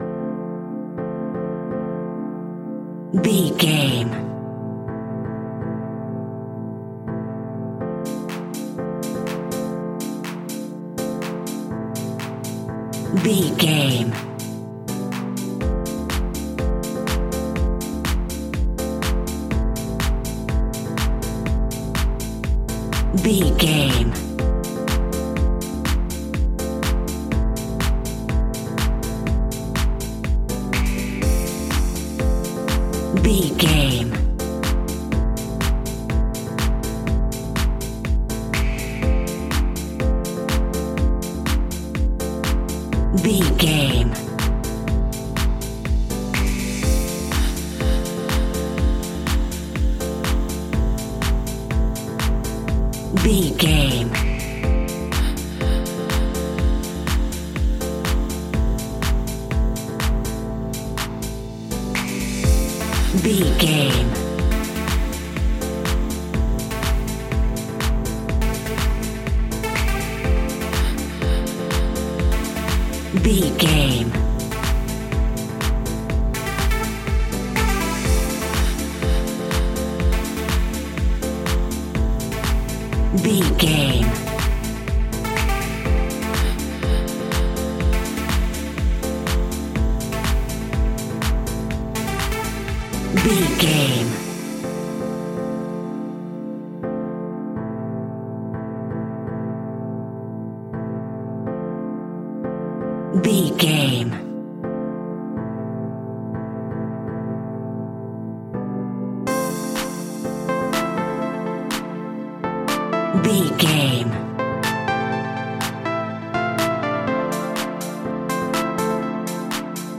Aeolian/Minor
groovy
smooth
futuristic
uplifting
drum machine
electro house
funky house
instrumentals
synth drums
synth leads
synth bass